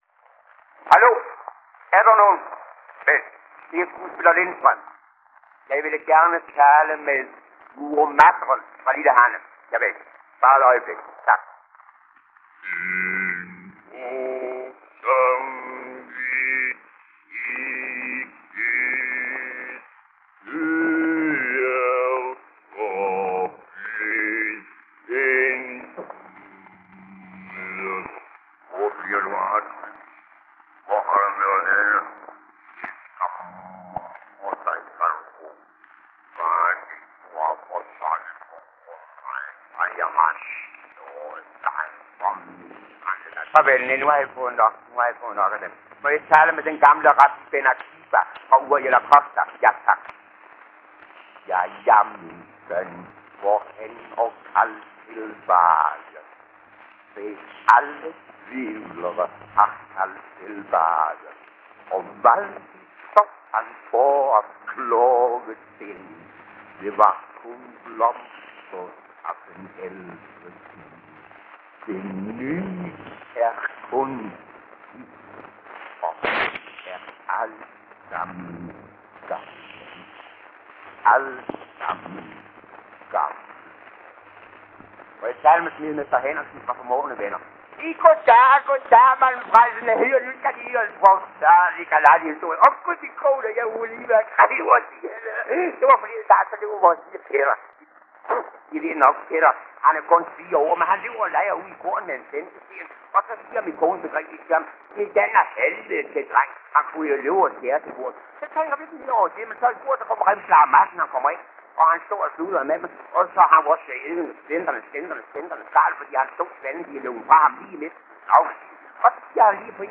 Sketch, hvor skuespiller Viggo Lindstrøm taler i telefon med fem karakterer, som han har spillet på Dagmarteatret 1892-94. Hver karakter bidrager med replikker fra de respektive skuespil: Murer Mattern fra Lille Hanne; Rabbi Ben Akiba fra Uriel Acosta; Smedemester Henriksen fra Formaaende Venner (Vilhelm Malling); Hushovmester Bergkvist fra Skandalen i Nat; og Redaktør Jakob Knudsen, Bostrup Tidende fra Henning Tondorf.